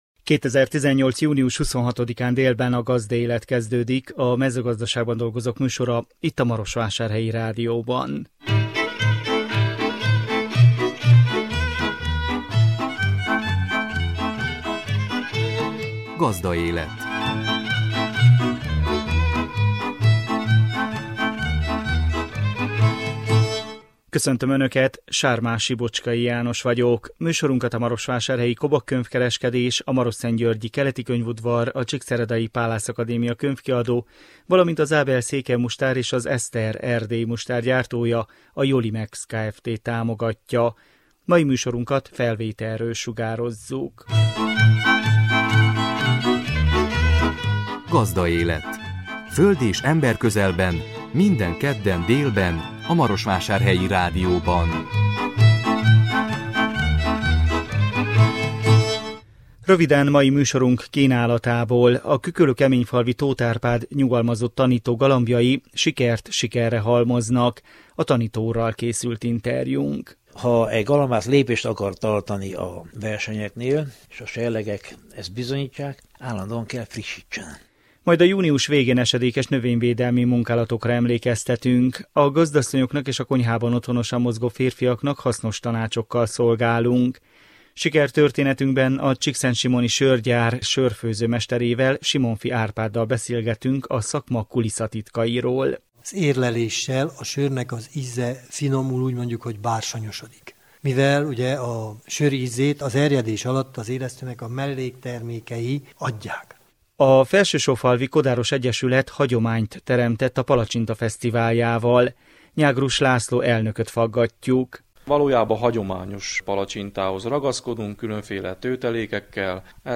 A tanító úrral készült interjúnk. Majd a június végén esedékes növényvédelmi munkálatokra emlékeztetünk. A gazdasszonyoknak és a konyhában otthonosan mozgó férfiaknak hasznos tanácsokkal szolgálunk.